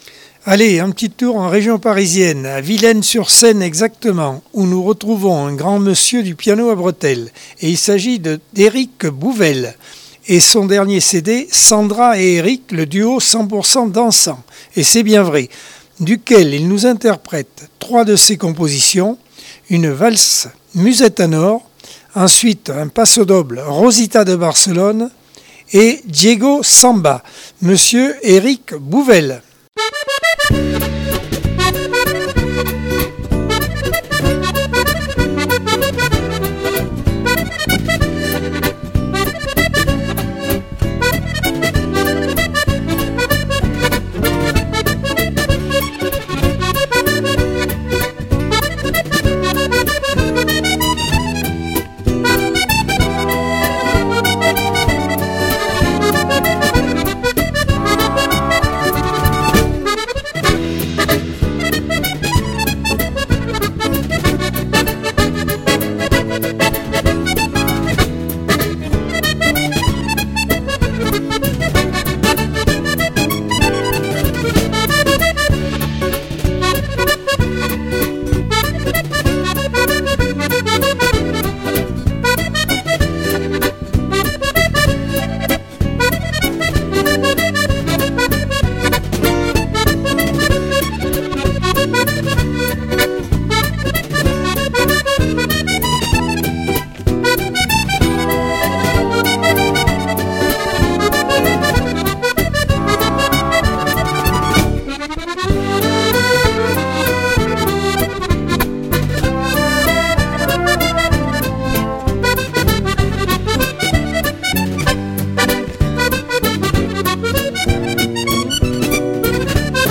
Accordeon 2022 sem 45 bloc 2.